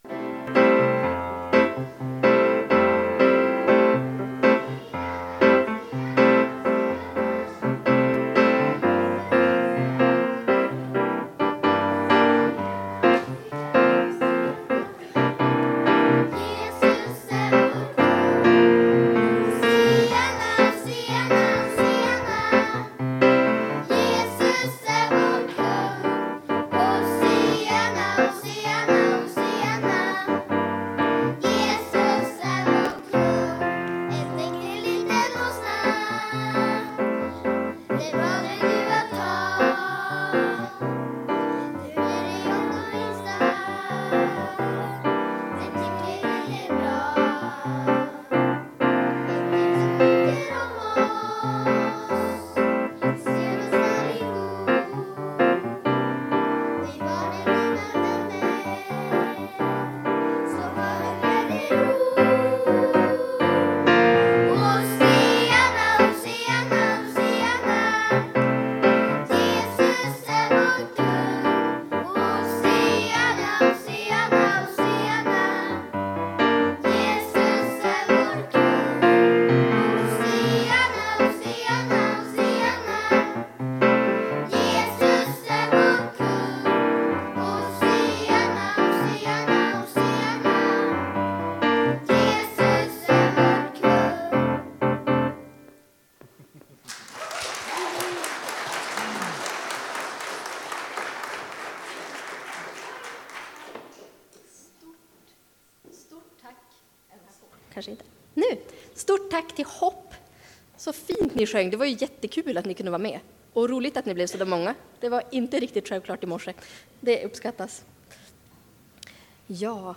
Gudstjänsten var välbesökt och avrundades med gemensamt adventsfika i Arken.